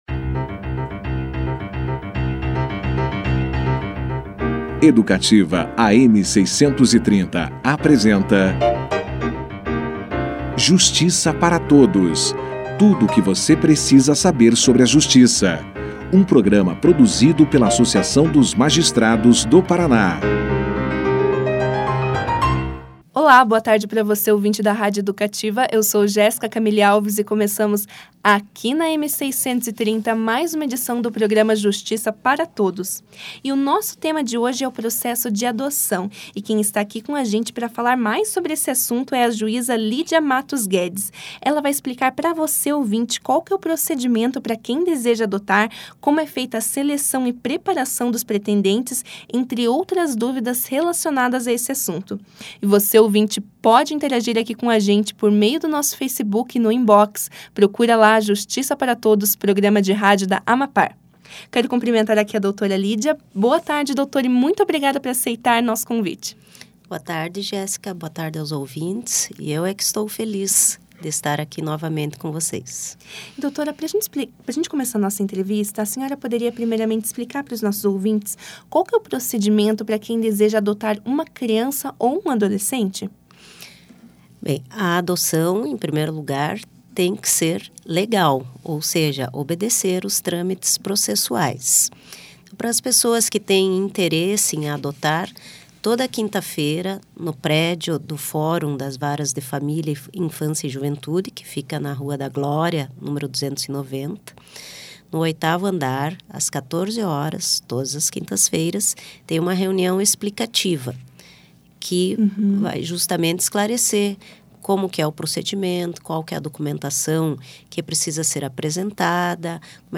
Confira na íntegra a entrevista com a juíza Lídia Mattos Guedes